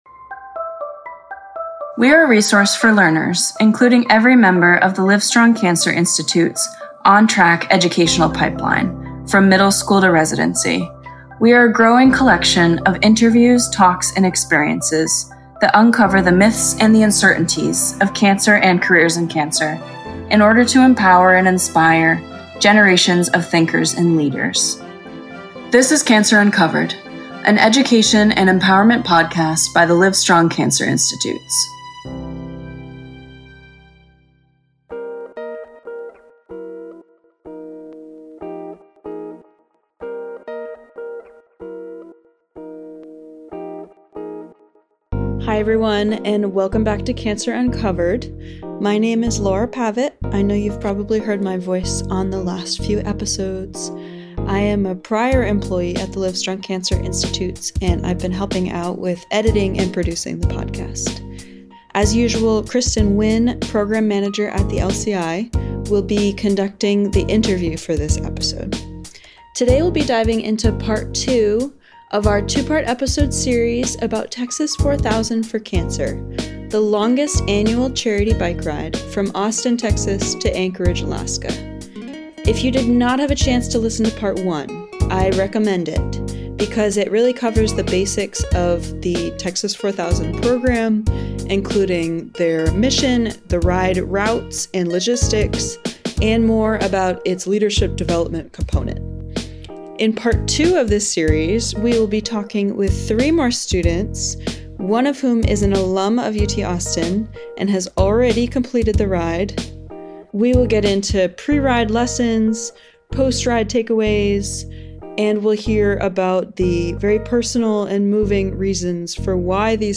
In Part 2 of this episode series, we hear from three more UT Austin students in the Texas 4000 for Cancer Program, one of whom has already completed the ride to Alaska. We discuss pre-ride lessons, post-ride takeaways, and the importance of support services for individuals and families facing cancer.